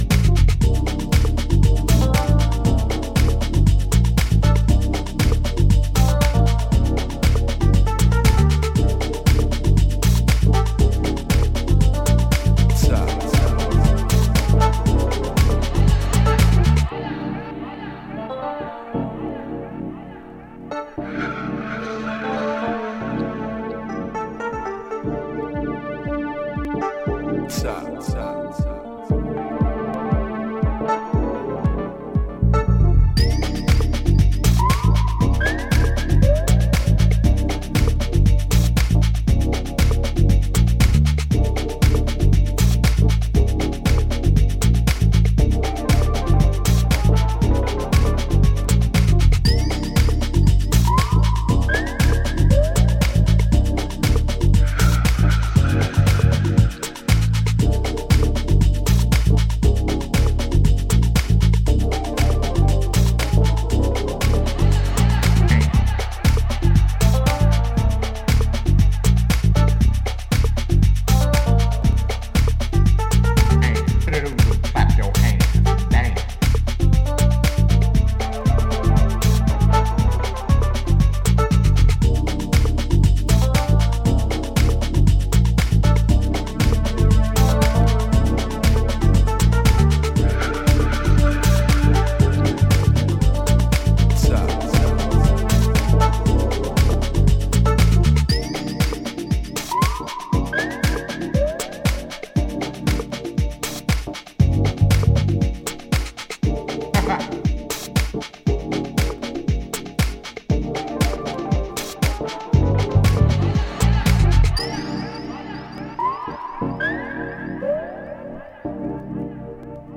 柔らかい浮遊コードやスペーシーなシンセを駆使しながら、じっくりとビートダウン・ハウスを繰り広げています。